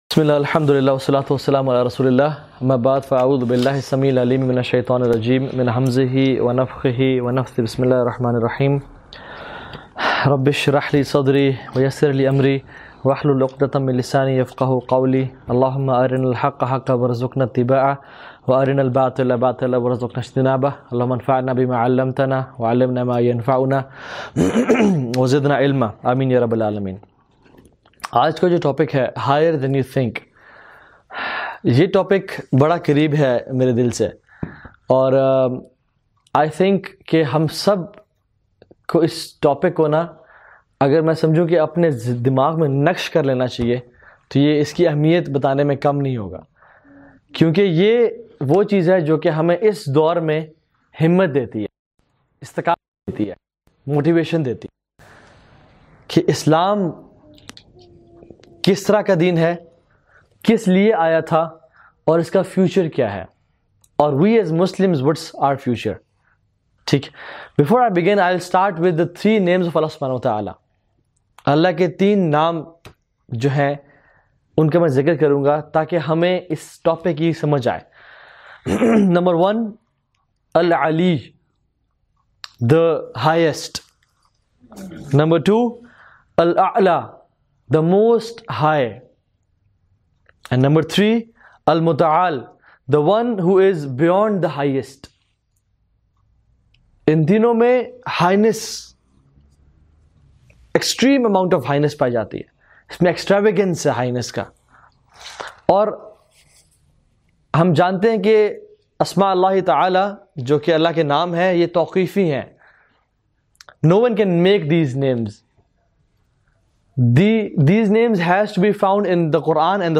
ALLAH'S HELP IS HERE! ｜｜ Encouraging Lecture